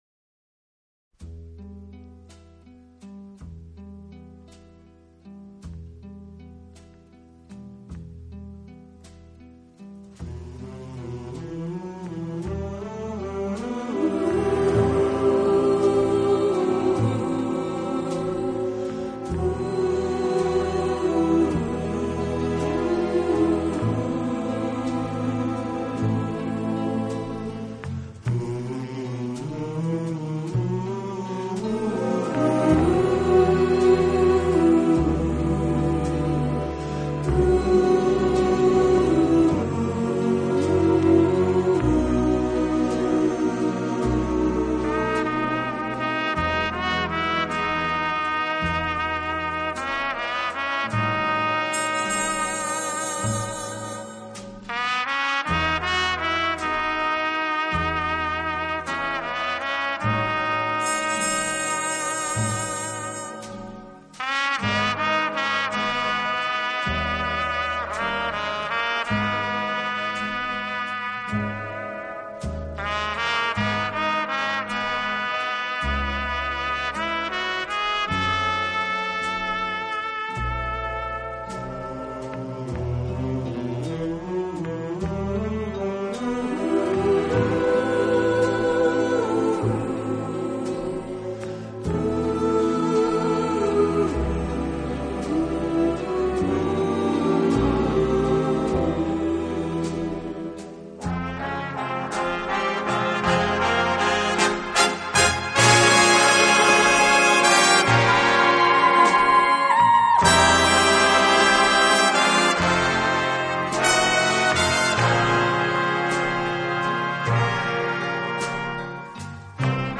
【轻音乐专辑】
小号的音色，让他演奏主旋律，而由弦乐器予以衬托铺垫，音乐风格迷人柔情，声情并
茂，富于浪漫气息。温情、柔软、浪漫是他的特色，也是他与德国众艺术家不同的地方。